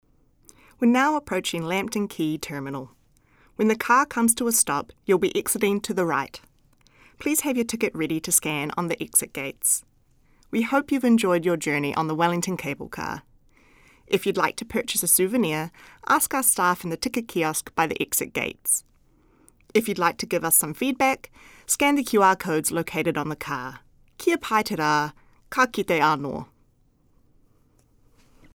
Audio Commentary
After passing Clifton Station.